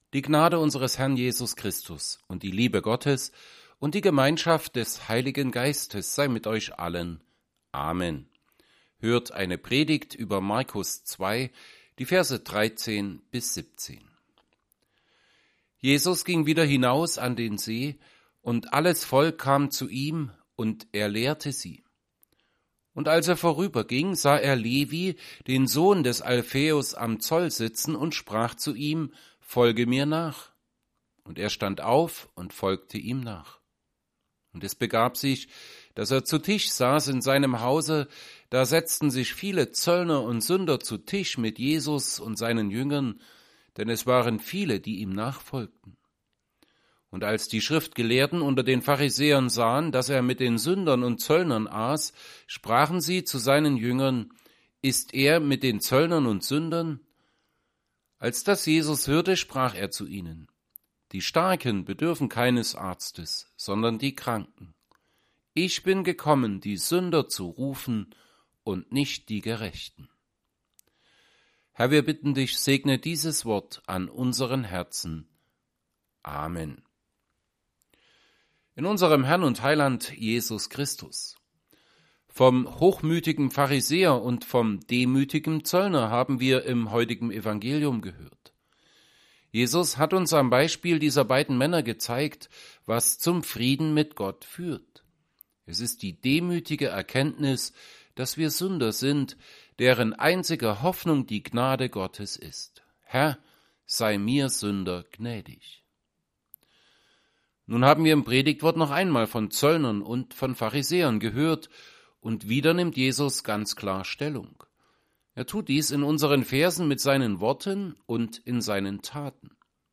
Predigt_zu_Mk_2_13_17.mp3